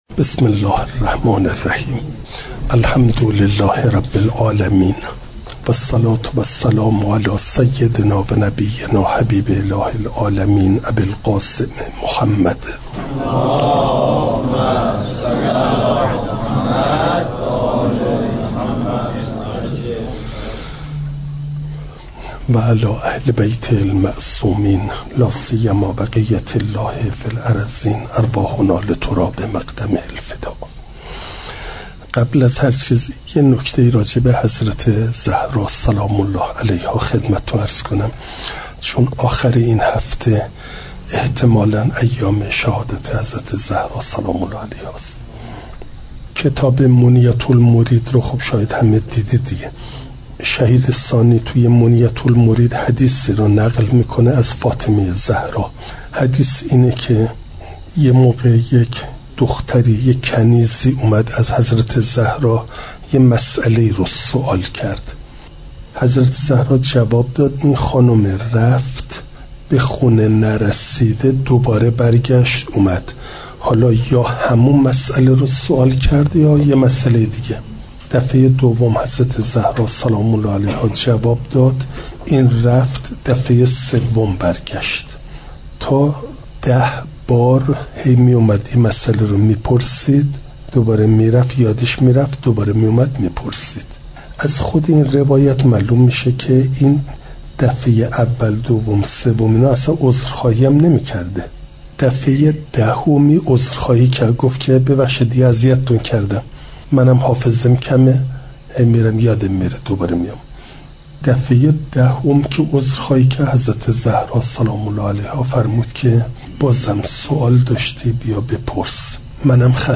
درجمع طلاب مدرسه علمیه جانبازان
یکشنبه ۲۰ آبانماه ۱۴۰۳، حوزه علمیه حضرت ابوالفضل علیه السلام(جانبازان)